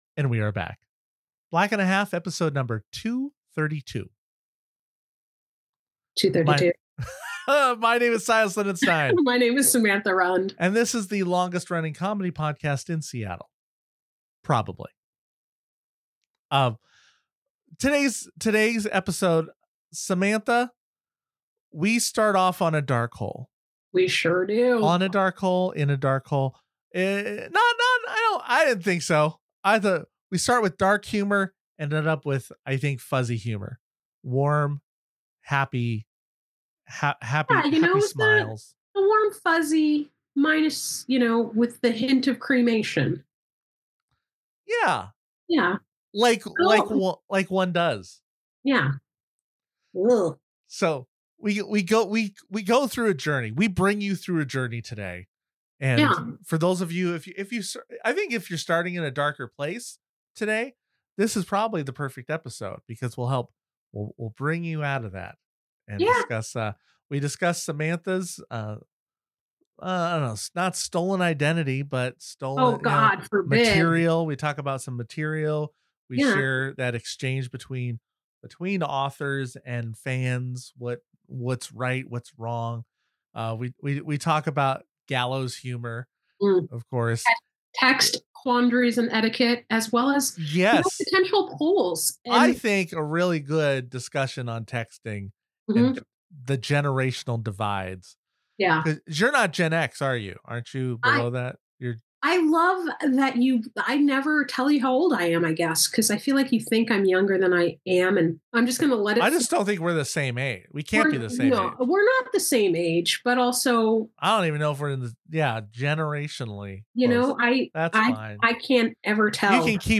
The longest running comedy podcast in Seattle.